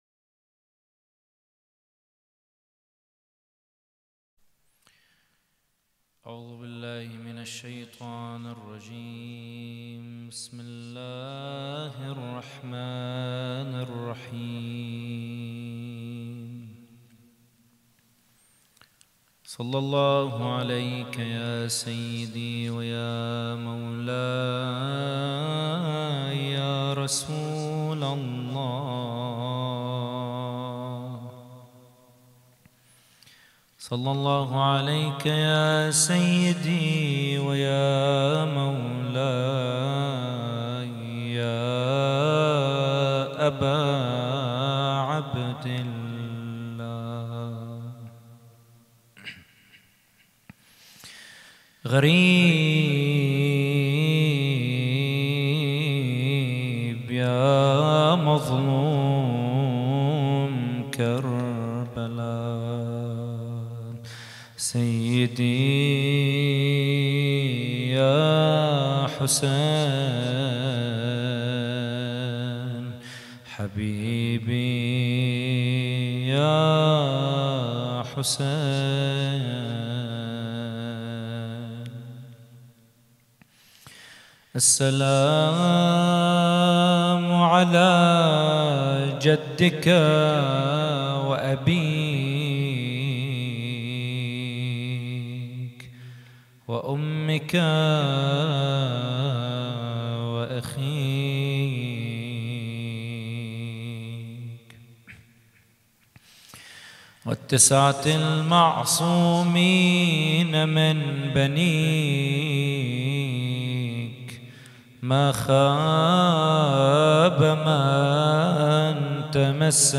محاضرة
احياء الليلة الثالثة من محرم 1442 ه.ق - هیأت رایة العباس لبنان